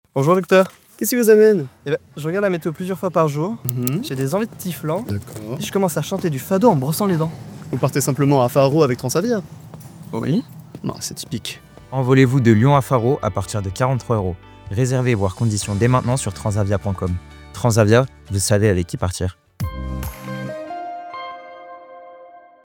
Silence + analyse